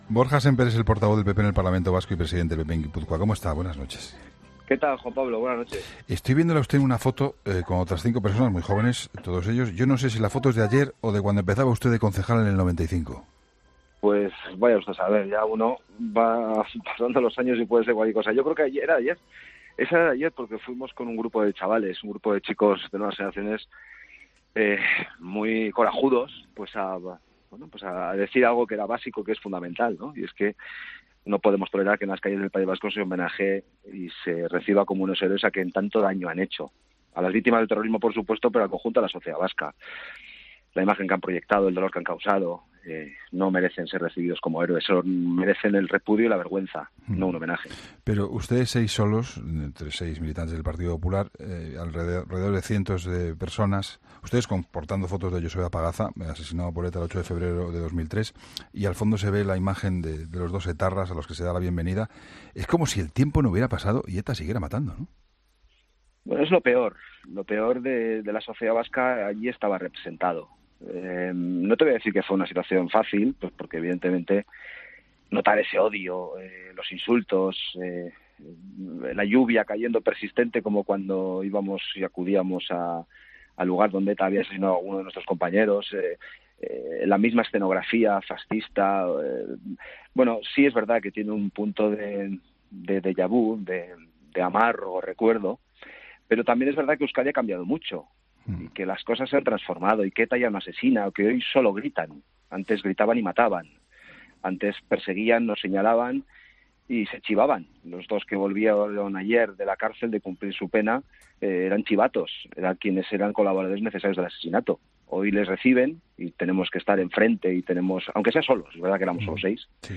Entrevistas en La Linterna
El portavoz del Grupo Popular en el parlamento vasco y Presidente del PP de Guipúzcoa, Borja Sémper, ha analizado en 'La Linterna' de Juan Pablo Colmenarejo la situación que vivió en la tarde del domingo junto a un grupo de militantes del PP que hicieron frente al homenaje a dos terroristas